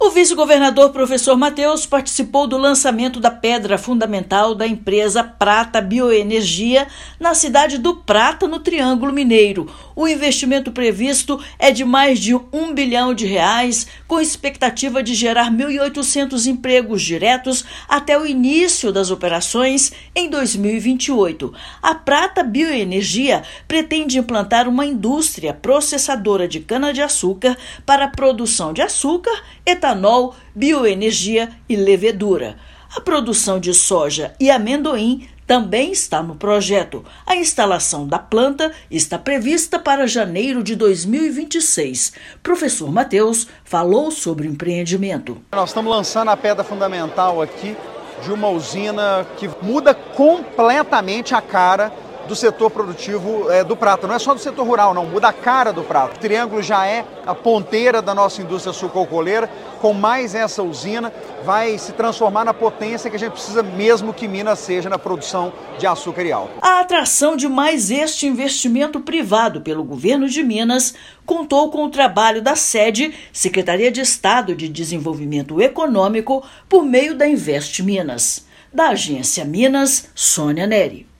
Prata Bioenergia será instalada no município do Prata, no Triângulo Mineiro; vice-governador destaca que usina vai mudar completamente a cara do setor produtivo de açúcar e álcool. Ouça matéria de rádio.